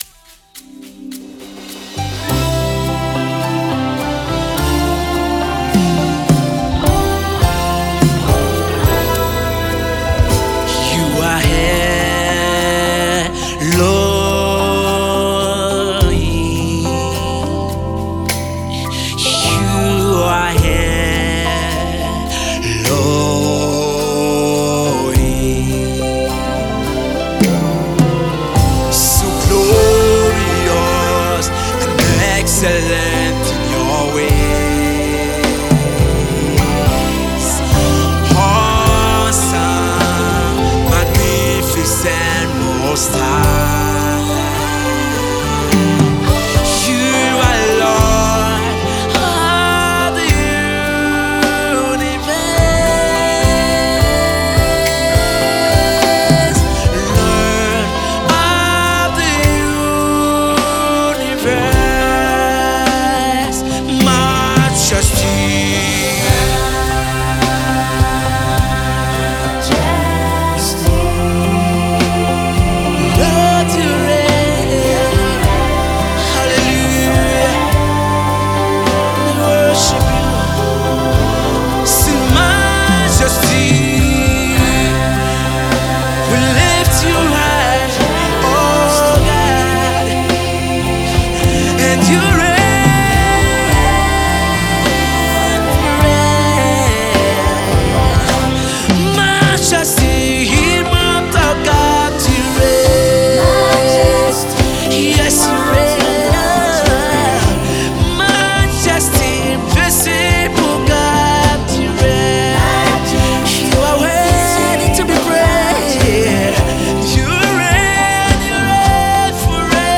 GOSPEL NEWSNEW MUSICNEWS!NIGERIA